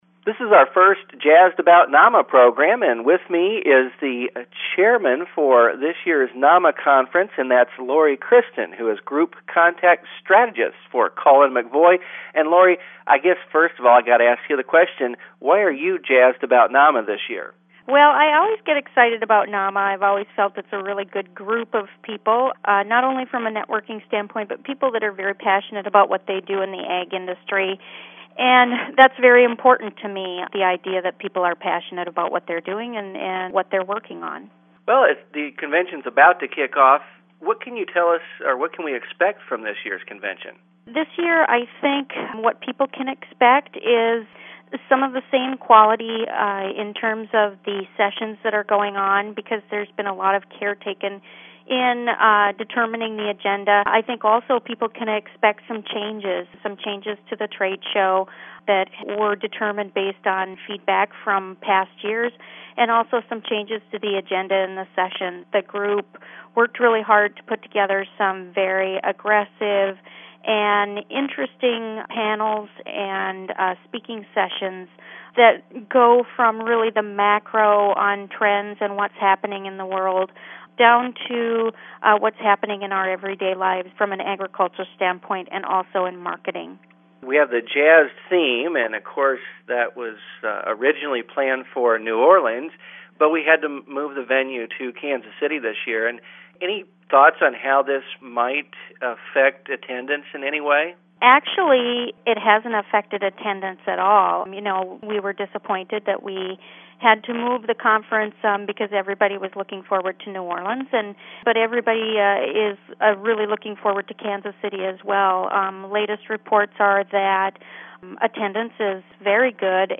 You can listen to a short interview with her as she looks forward to the culmination of a year’s worth of work by her committee and the staff.
Interview